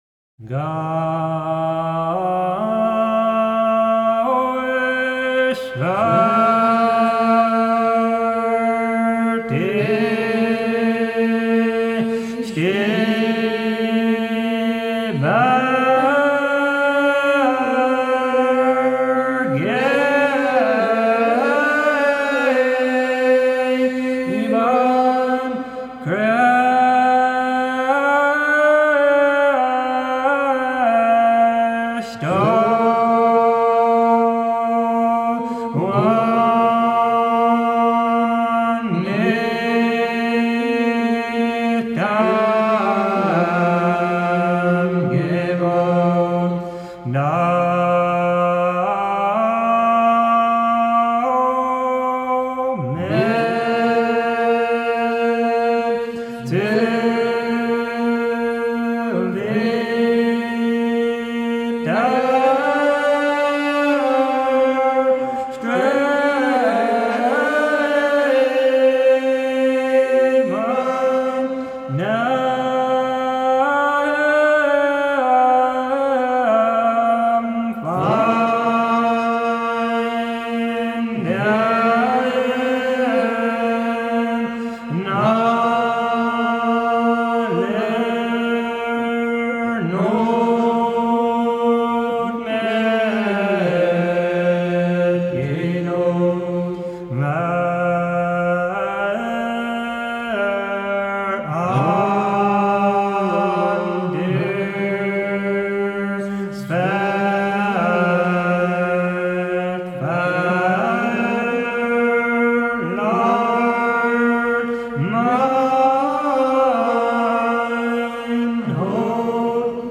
amishmusic-spiritssword.mp3